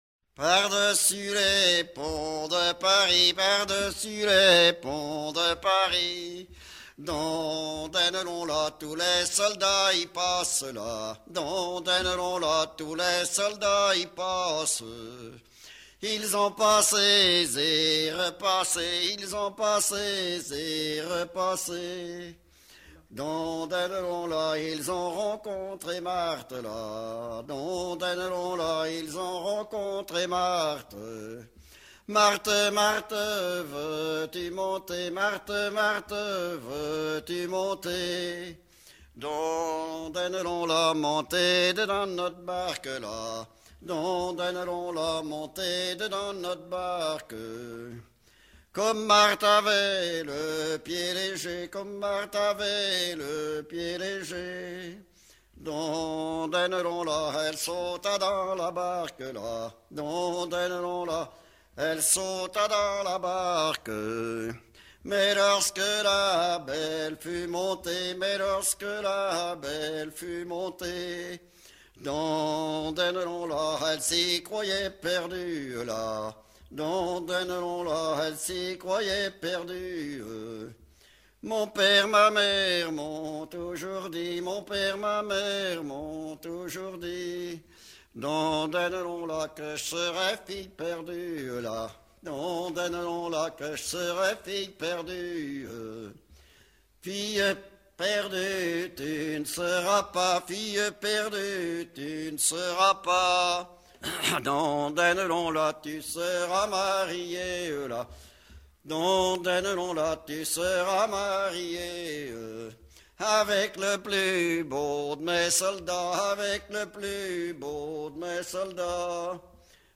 Localisation Boufféré
Genre laisse